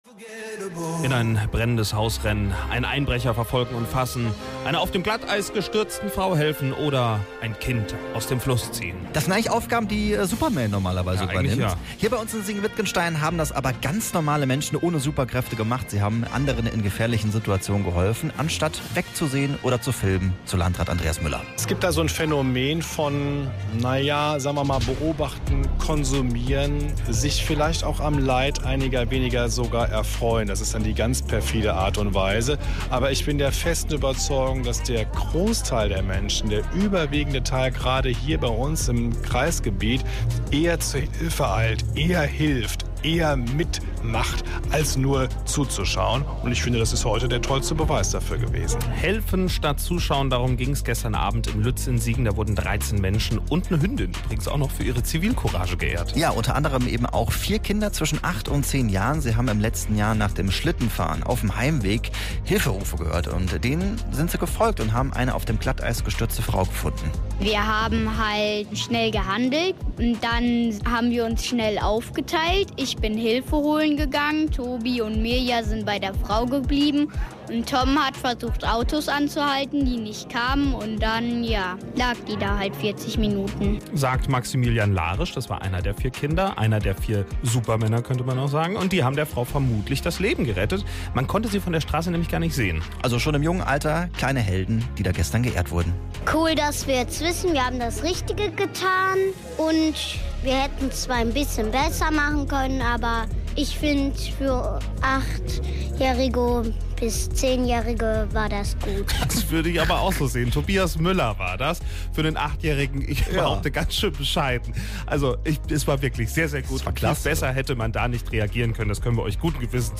Beitrag bei Radio Siegen "Am Morgen"